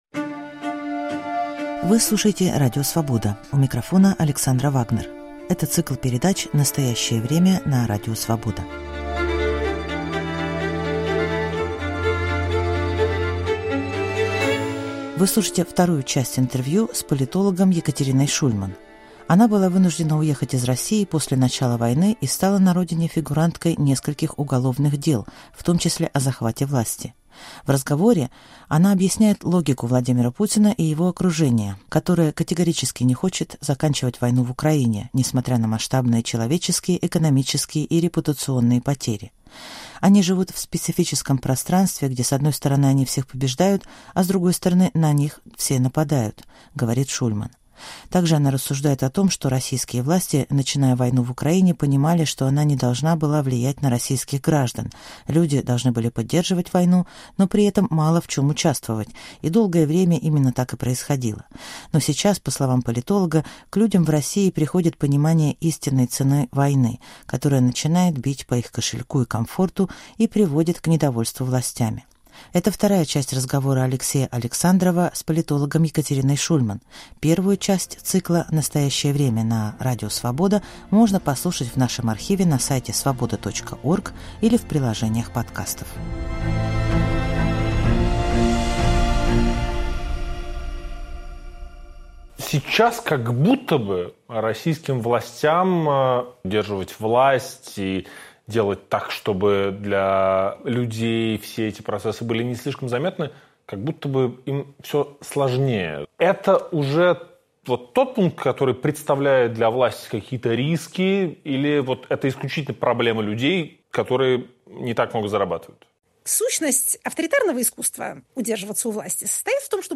отвечает политолог Екатерина Шульман